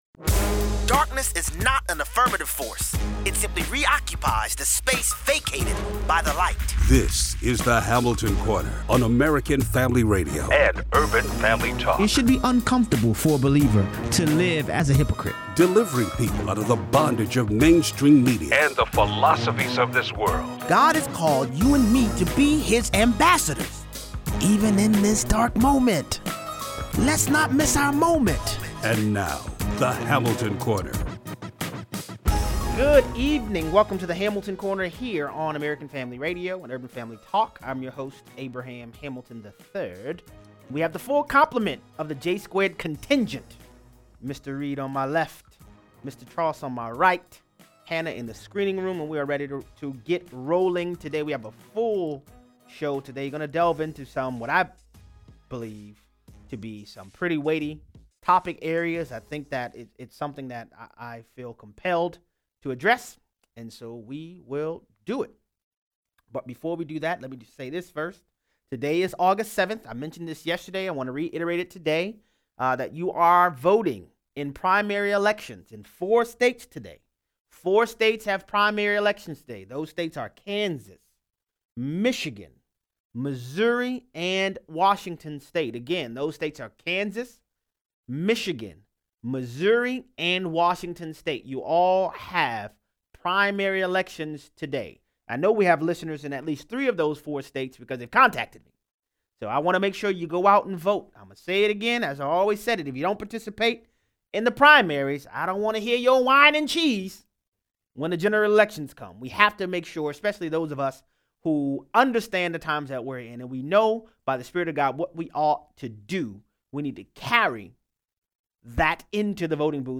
We must call Bible things by Bible names. Racism is an iteration of the sin of partiality. 0:43 - 0:60: Coordinated tech censorship is here folks. Callers weigh in.